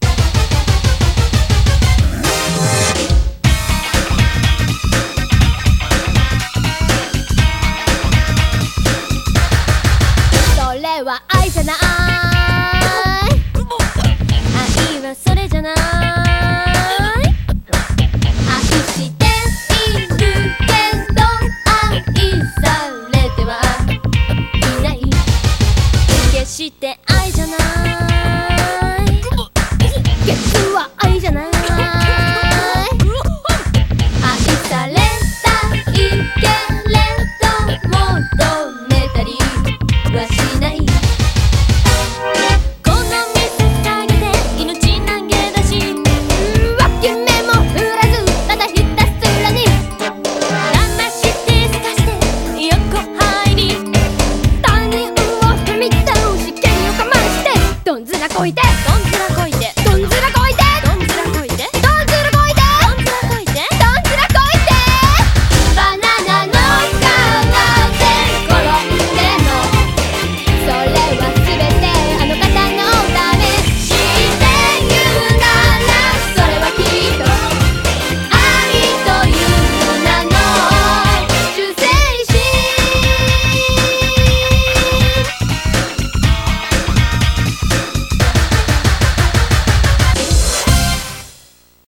BPM122
Audio QualityLine Out